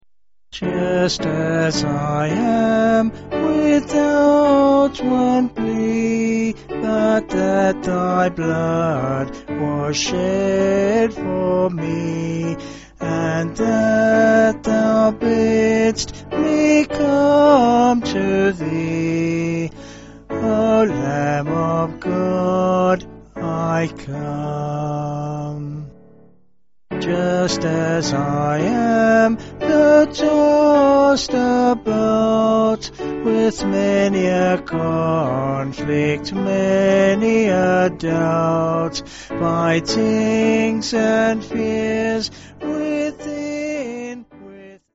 Vocals and Piano